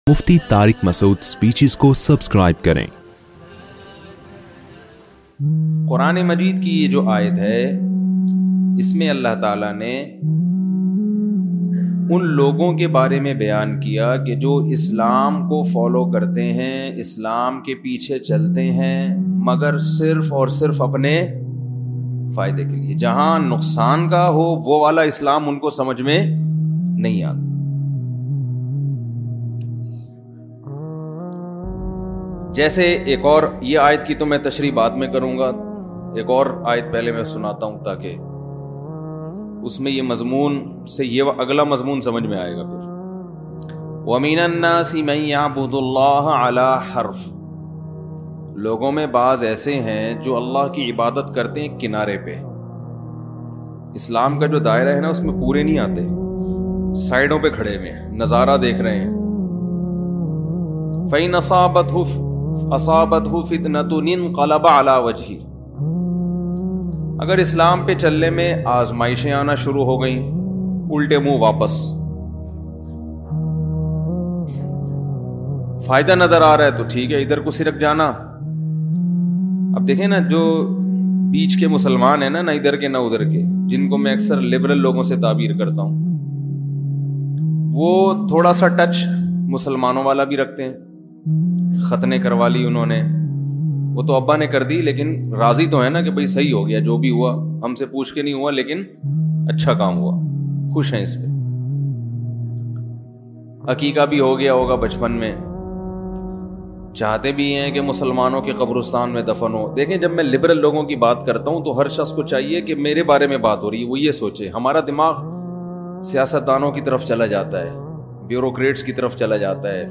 Speeches